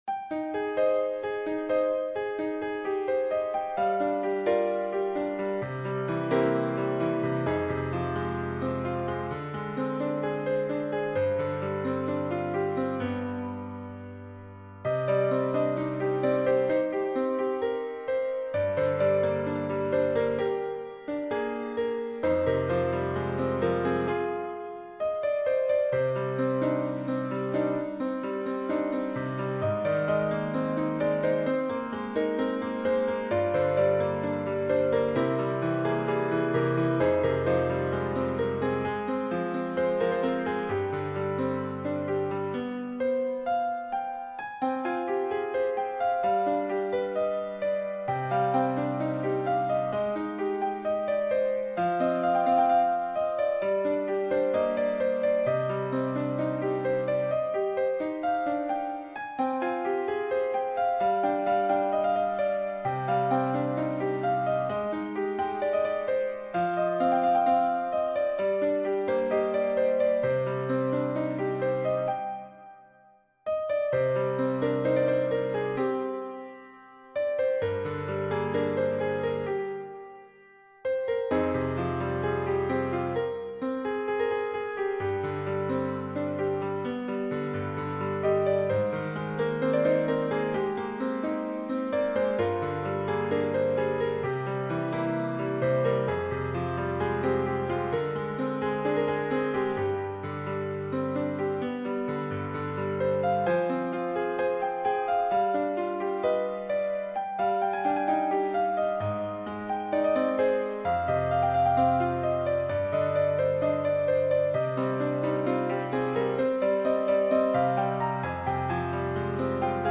müasir pop janrında